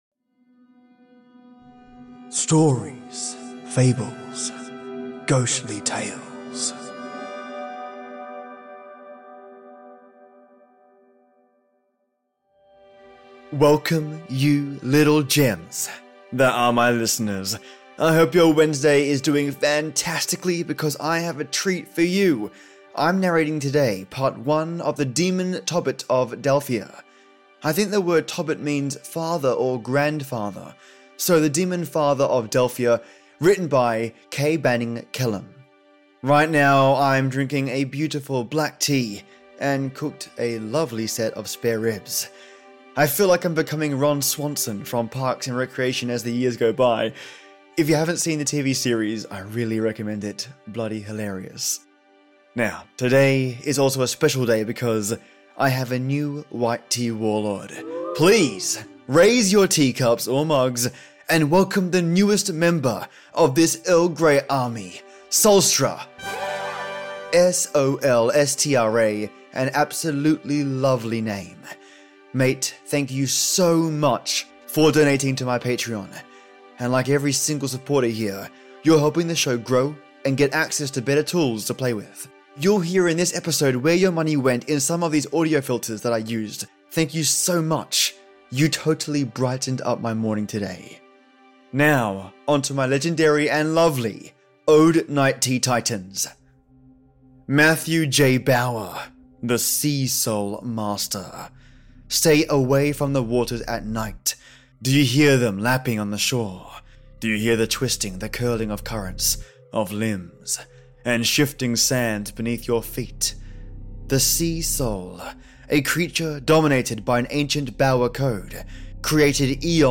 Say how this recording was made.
You’ll hear in this episode where your money went in some of the audio filters.